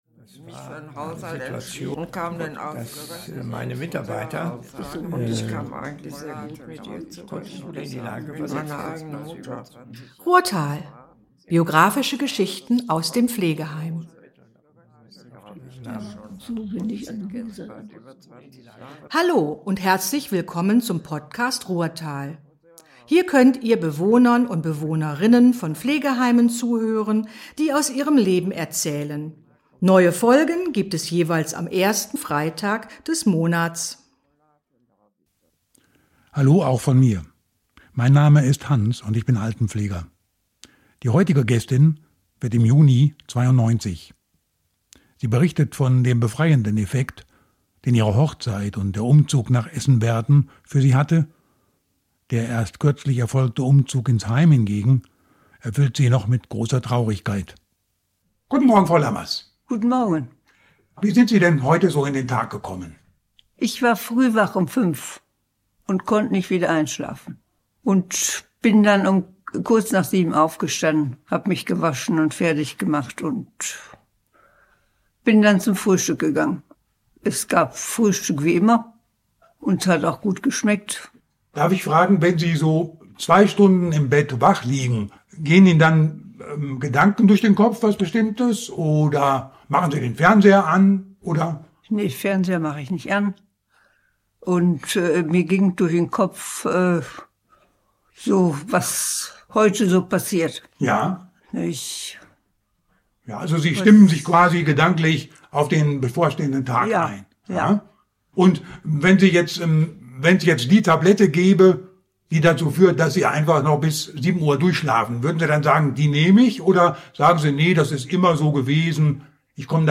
Die Gästin dieser Folge erzählt von ihrer Kindheit in Salzbergen, von Bombenangriffen im Krieg, einem Schutzraum, dem Postkeller, in dem sie sich befand als dieser von einer Bombe getroffen wurde.…